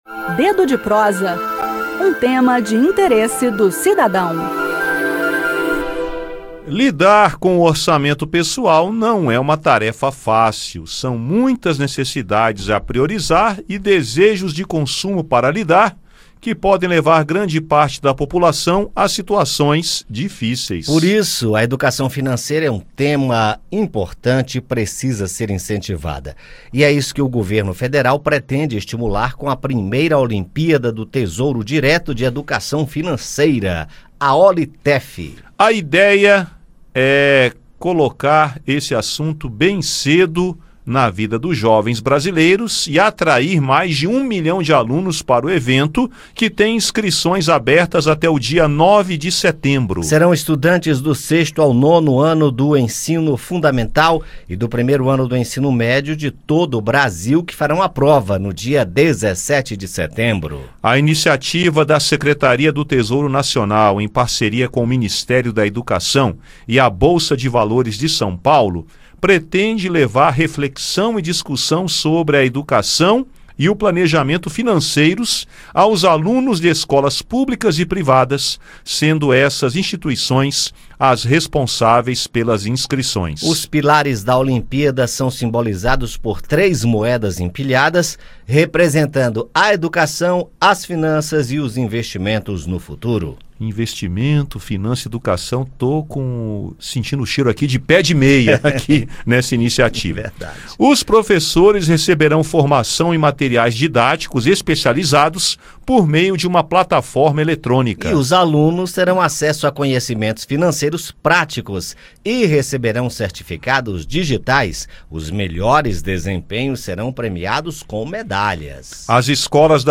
No bate-papo, entenda a iniciativa e suas premiações e saiba como as escolas podem se inscrever para participar.